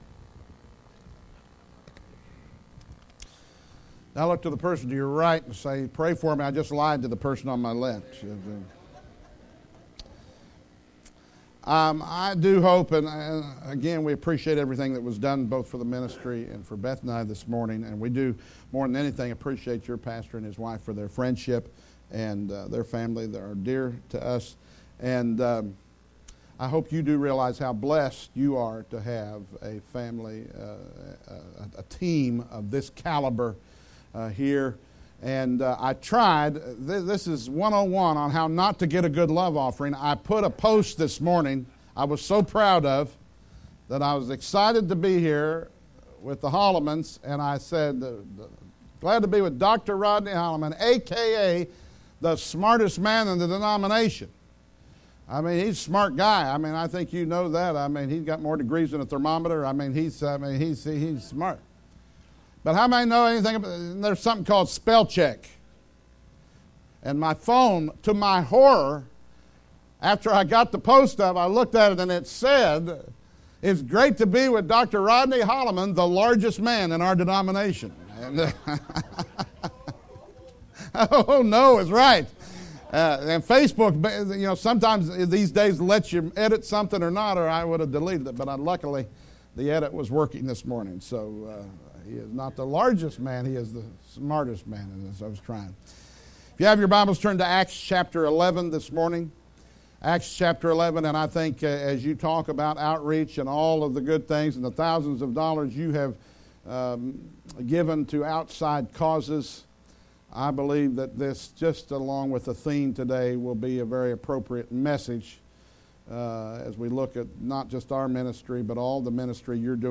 Acts 11:19-26 Service Type: Special Services Has Anybody Seen Grace?